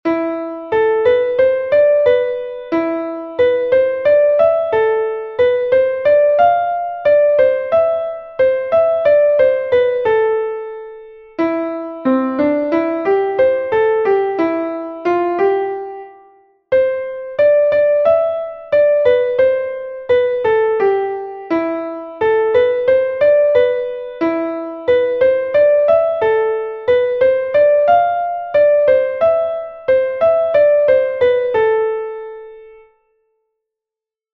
- Exercise 1: A minor (bars 1 to 4) and D major (bars 5 to 8).
melodic_reading_1.mp3